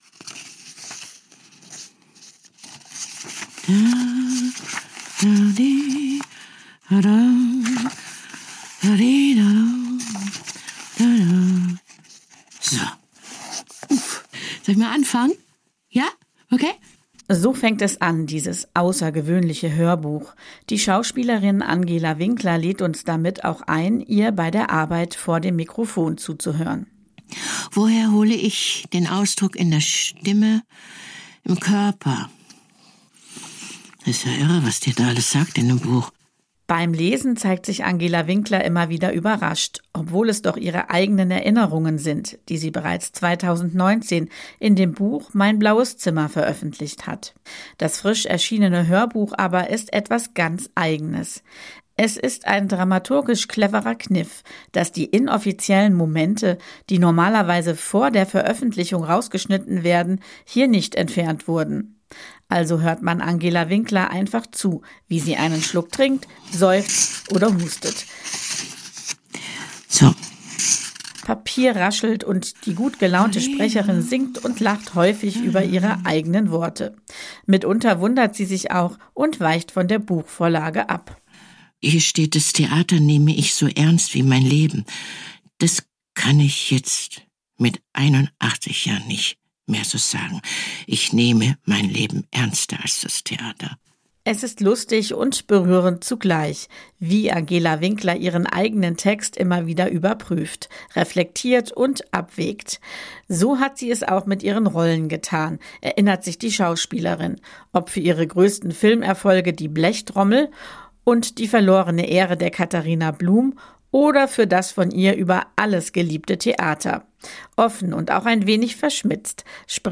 Ihre bereits 2019 veröffentlichten biografischen Notizen hat sie jetzt auch als Hörbuch eingelesen. Angela Winkler schlägt einen Plauderton an. Gegenüber dem geschriebenen Buch überzeugt die Vertonung dramaturgisch durch einen besonderen Kniff: Auch die inoffiziellen Geräusche und spontanen Äußerungen von Angela Winkler als Sprecherin sind zu hören. Sie summt, lacht und trinkt einen Schluck Wasser, während sie ganz ungezwungen über ihr Leben und das geschriebene Wort reflektiert.